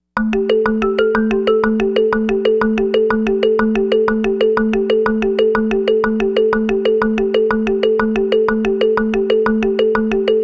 Hypno Xylo.wav